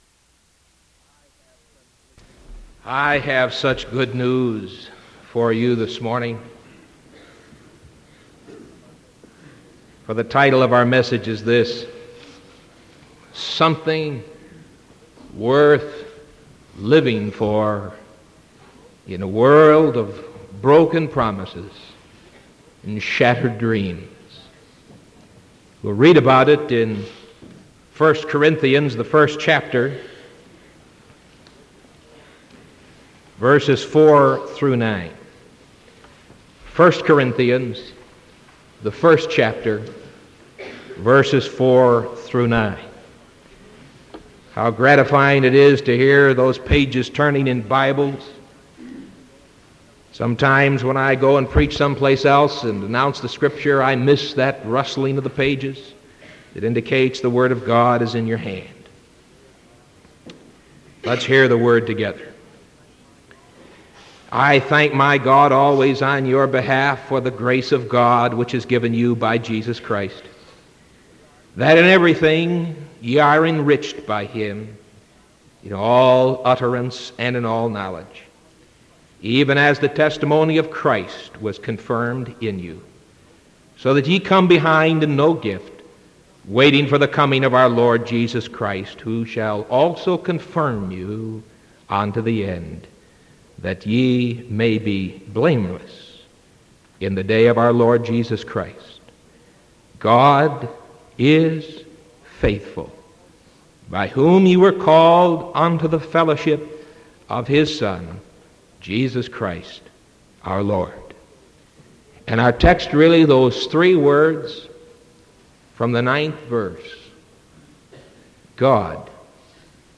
Sermon April 6th 1975 AM